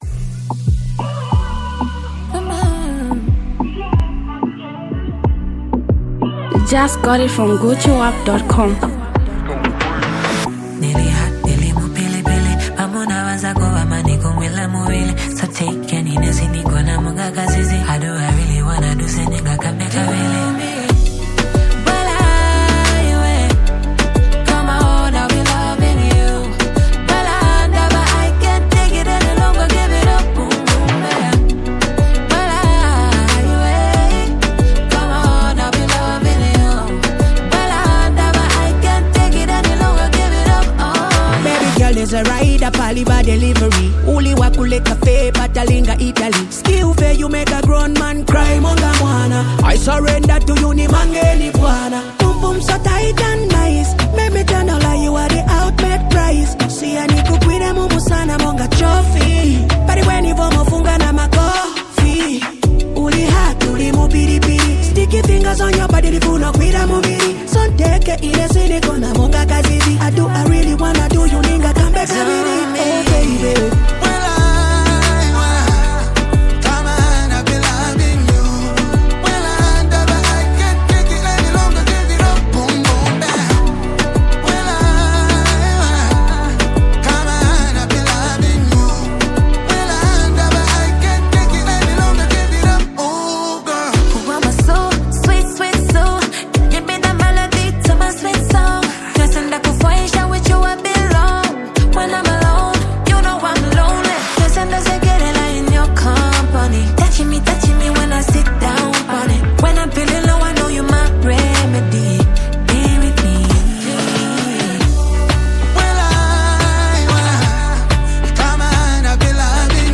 Zambian Mp3 Music
reggae dancehall